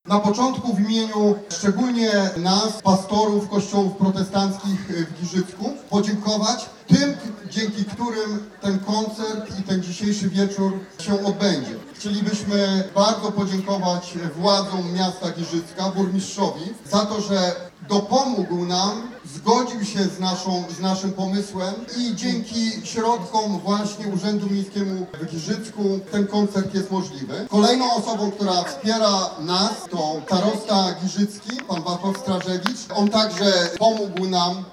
W sobotę (3.06) w ramach Dni Giżycka, na Plaży Miejskiej odbył się koncert muzyki gospel.